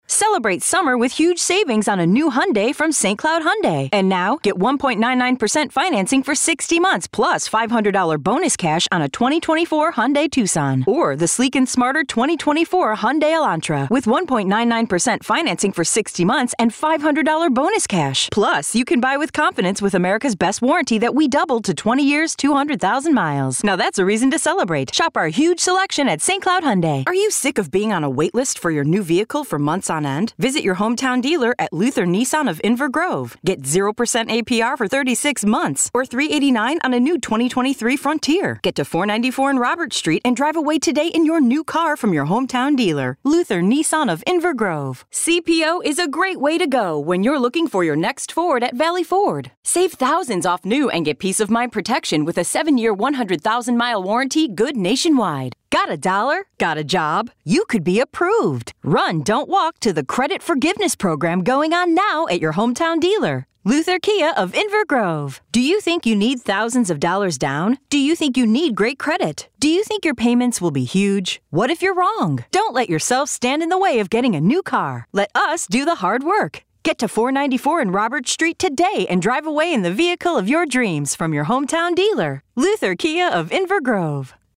Automoción
Micrófono MKH 415, Pro Tools, estudio insonorizado con funciones de masterización.